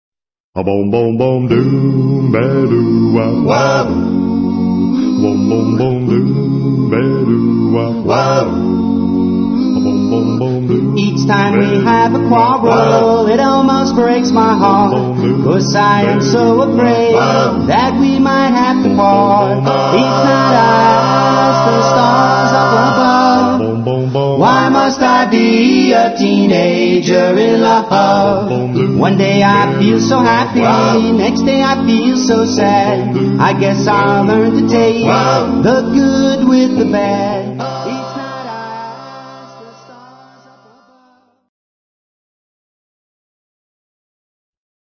authentic four-part harmonies